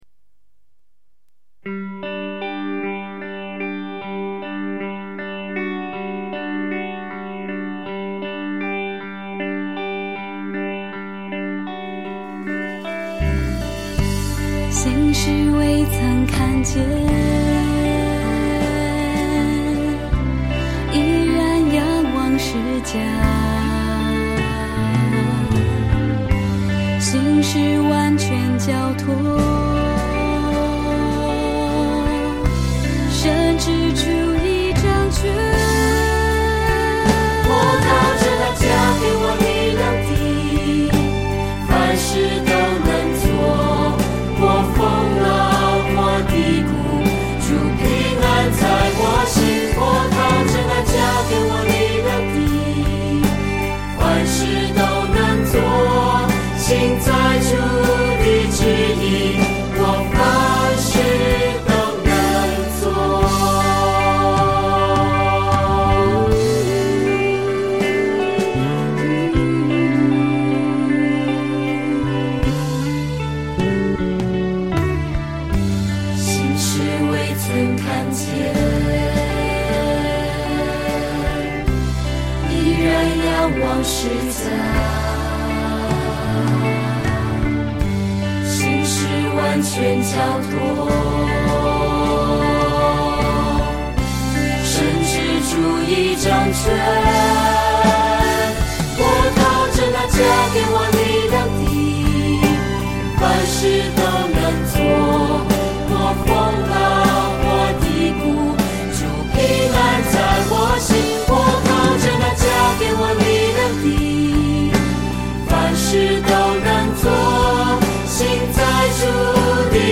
赞美诗《凡事都能做》